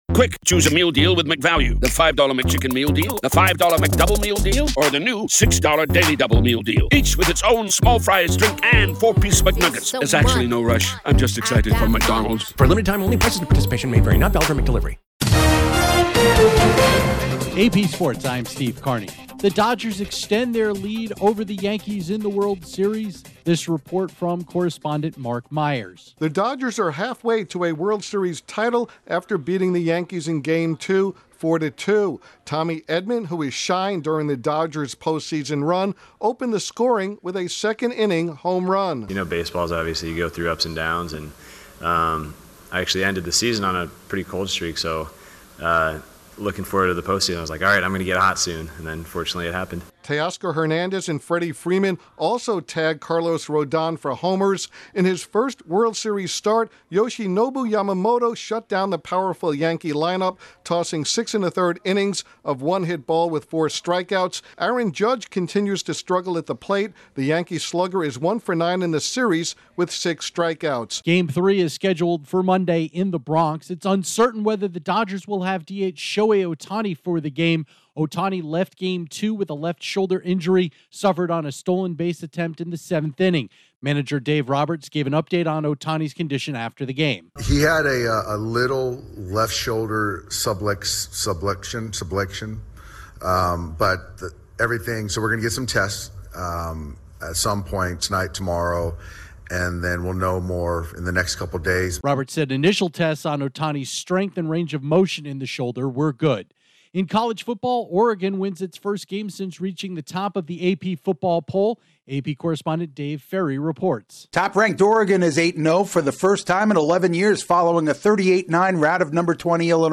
Update on the latest sports